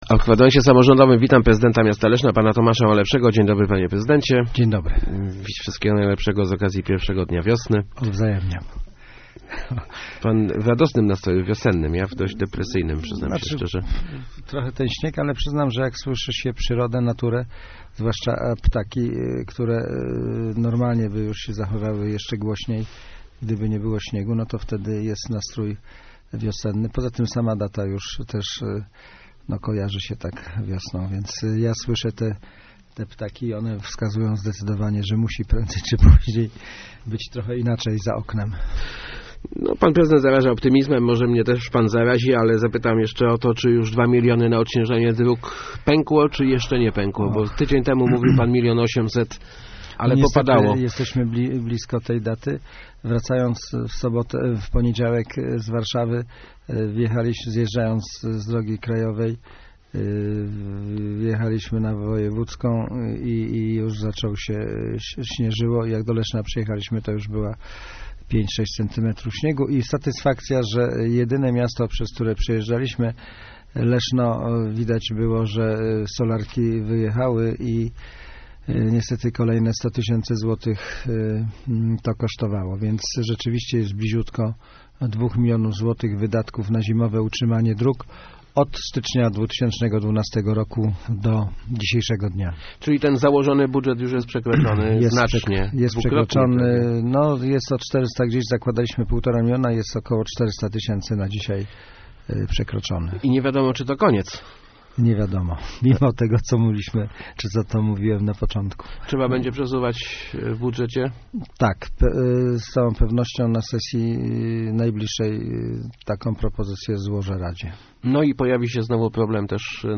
Gościem Kwadransa był prezydent Leszna Tomasz Malepszy. ...